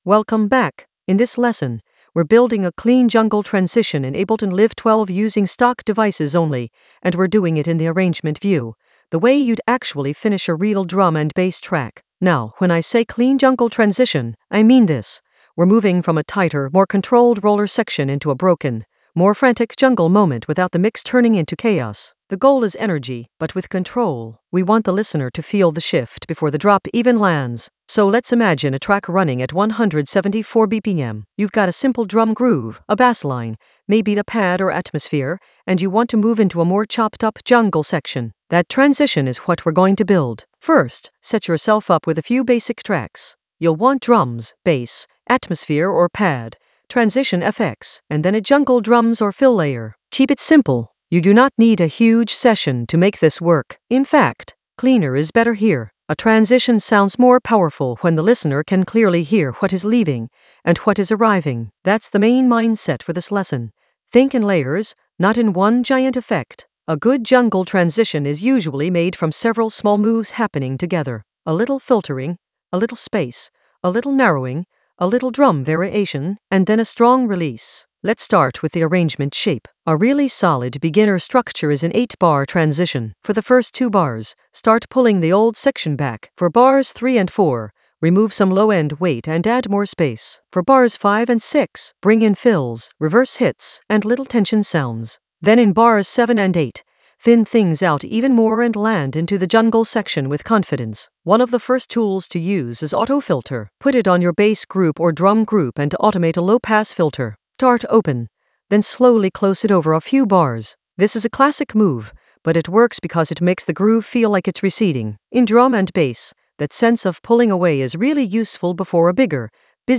An AI-generated beginner Ableton lesson focused on Clean jungle transition using stock devices only in Ableton Live 12 in the Arrangement area of drum and bass production.
Narrated lesson audio
The voice track includes the tutorial plus extra teacher commentary.